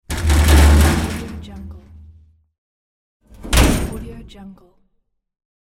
دانلود افکت صدای باز و بسته شدن درب فلزی قدیمی
صدای نوستالژیک درب فلزی قدیمی، در دو نسخه باز و بسته!
• صدای واقعی و باکیفیت: این افکت صدا با ظرافت کامل و با استفاده از تجهیزات حرفه‌ای ضبط شده است و صدایی واقعی و طبیعی از باز و بسته شدن درب فلزی قدیمی را به شما ارائه می‌دهد.
Sample rate 16-Bit Stereo, 44.1 kHz